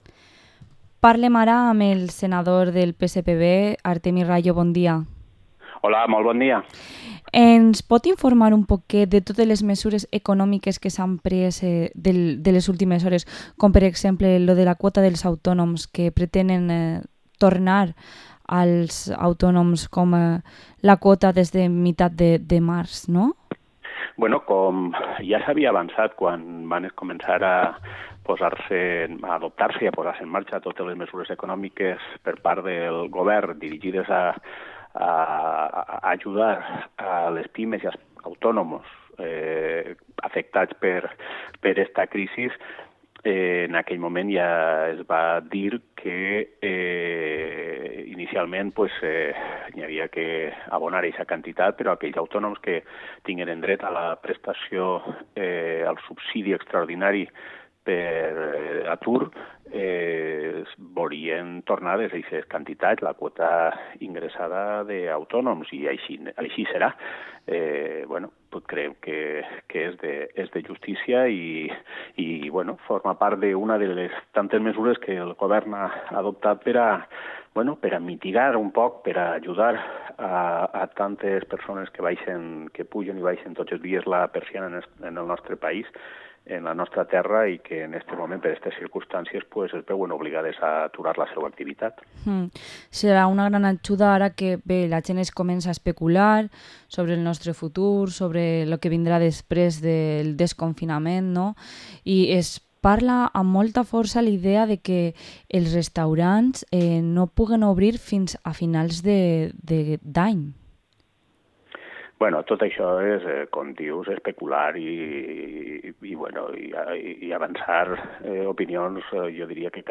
Entrevista al Senador del PSPV-PSOE, Artemi Rallo